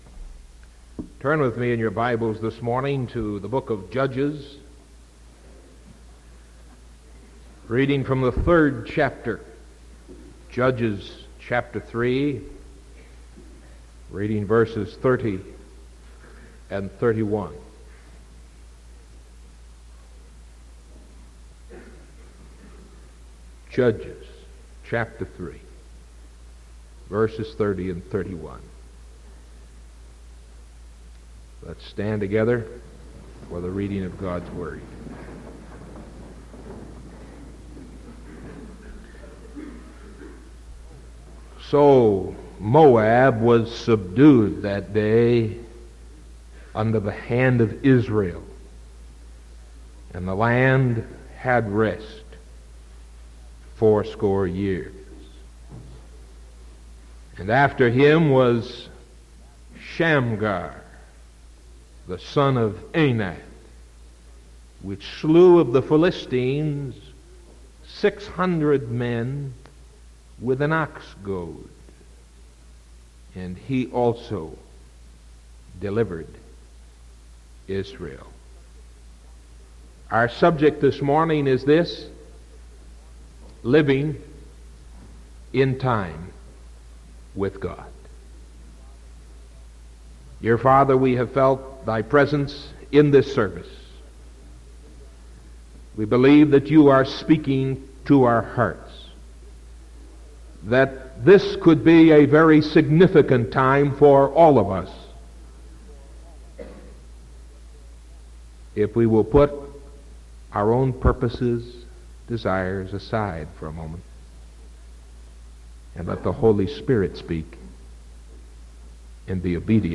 Sermon from December 8th 1974 AM